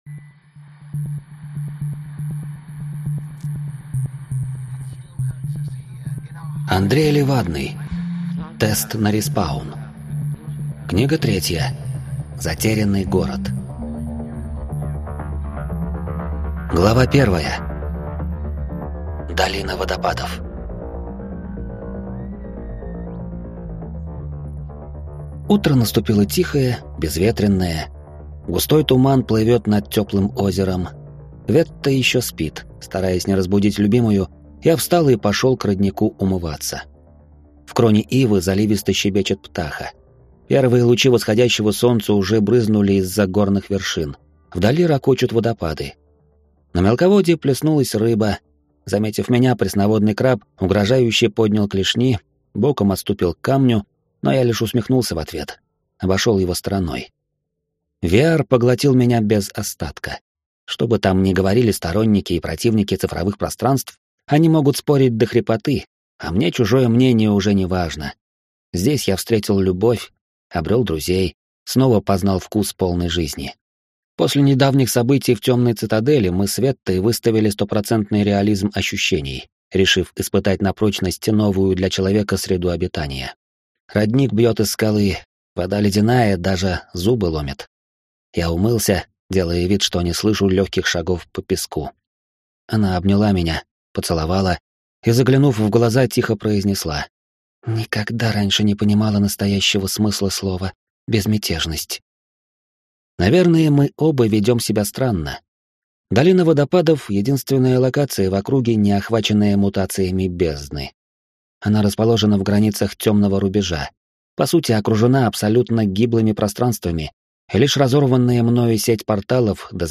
Аудиокнига Тест на респаун. Затерянный город | Библиотека аудиокниг